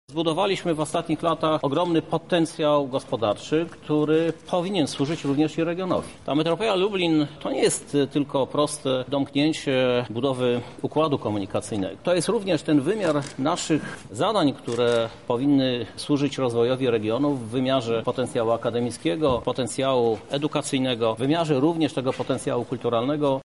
Prezydent chciałby także, by Lublin był Miastem Wszystkich Mieszkańców, a jednocześnie Metropolią Lublin, co sam wyjaśnia: